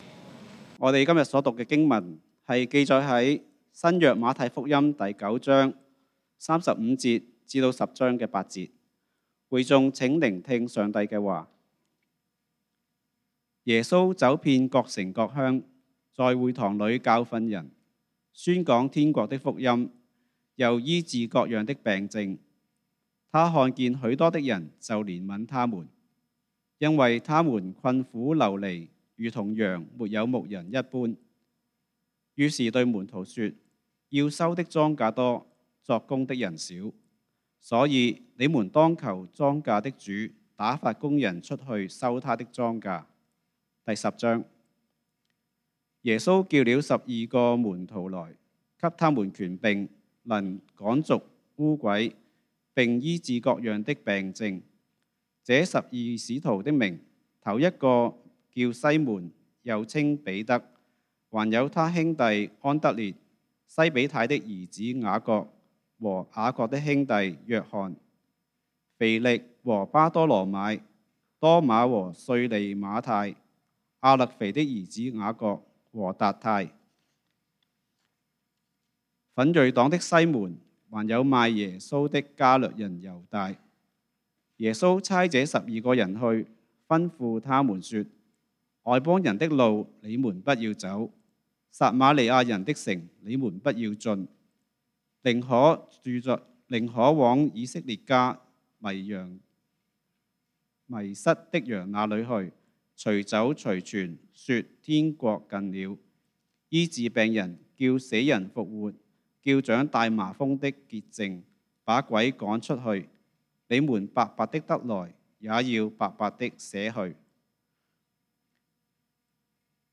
講道經文：《馬太福音》 Matthew 9:35-10:8 本週箴言：《詩篇》Psalms 116:12-14 「我拿甚麼報答耶和華向我所賜的一切厚恩？